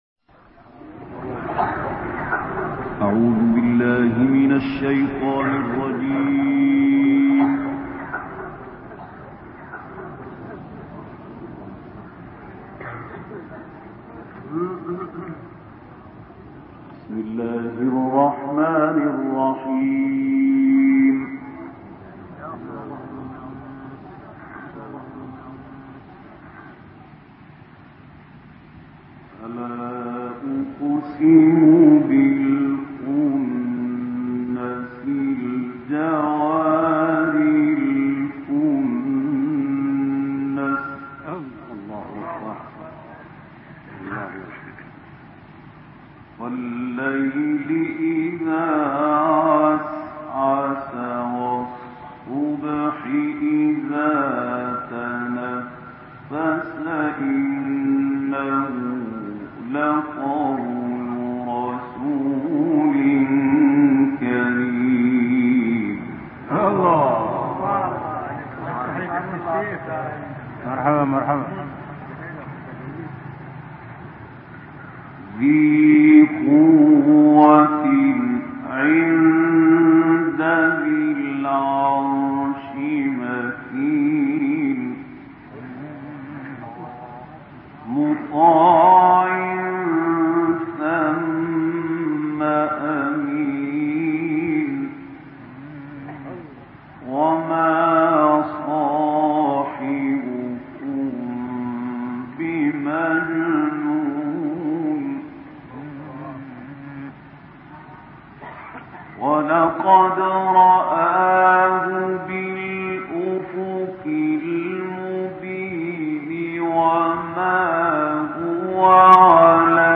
تلاوت آیاتی از کلام‌الله مجید با صوت «خلیل الحصری»
گروه فعالیت‌های قرآنی: تلاوت سوره‌های تکویر، انفطار و مطففین با صدای محمود خلیل الحصری را می‌شنوید.